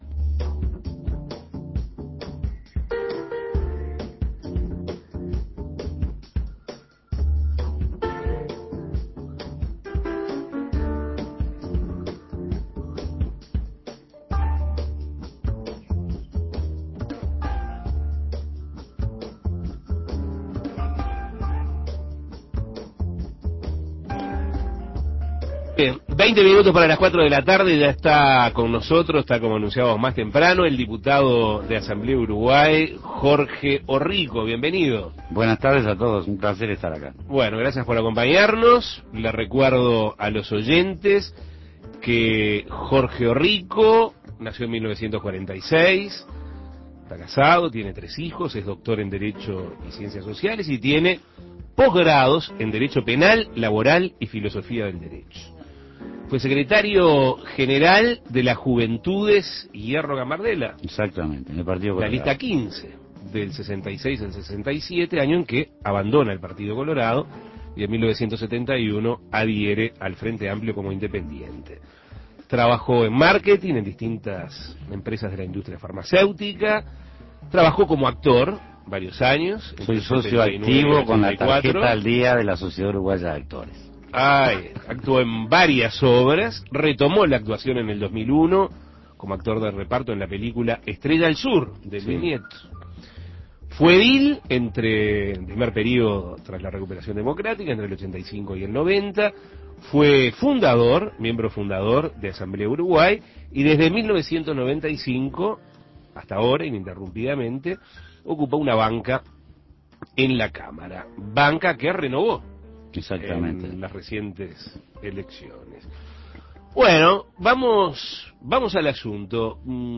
El diputado por Asamblea Uruguay Jorge Orrico dialogó sobre la situación interna del Frente Amplio, un supuesto cambio en la dirección de la campaña electoral de cara al balotaje de este domingo y cómo será la composición del próximo gobierno ante una eventual victoria de la fuerza política de izquierda, entre otros temas. Escuche la entrevista.